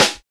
SNR XC.SNR00.wav